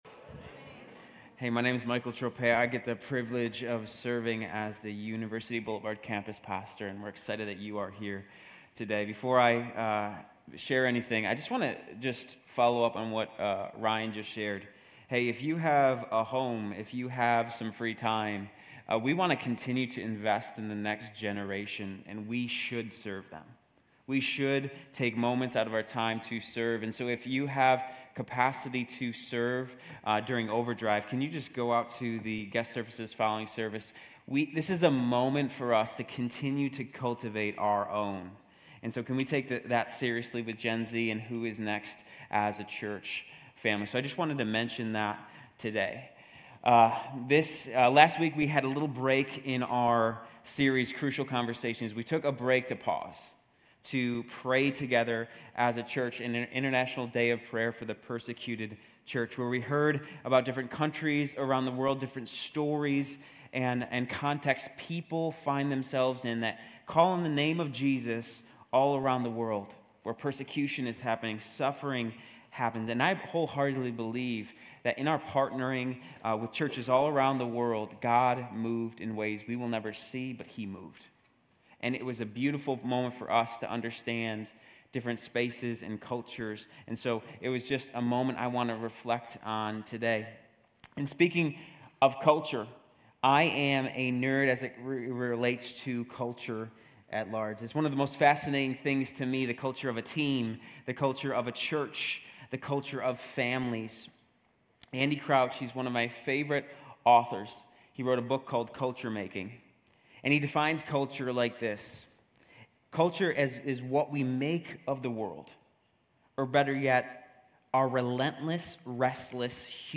Grace Community Church University Blvd Campus Sermons Crucial Conversations: Acts 17:16-34 Nov 12 2023 | 00:40:21 1x 00:00 / 00:40:21 Subscribe Share RSS Feed Share Link Embed
GCC-UB-November-12-Sermon.mp3